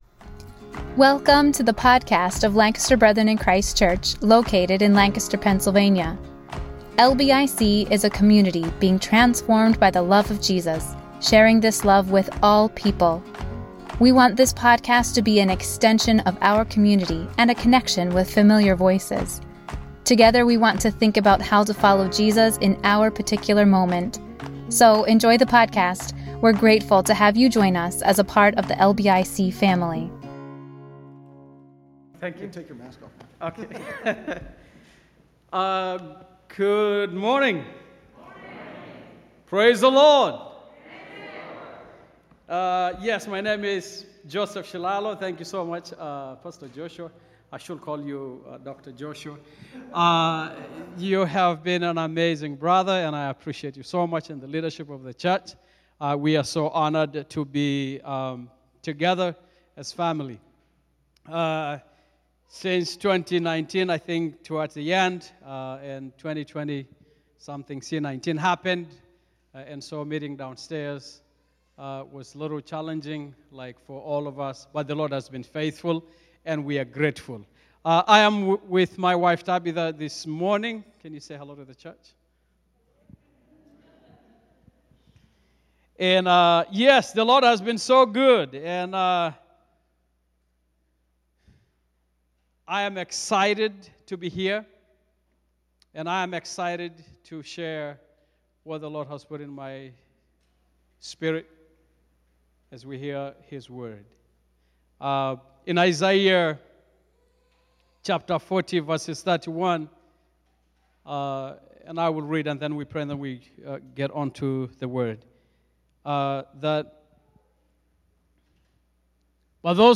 Message: “Sabbatical Q&A ” from Multiple People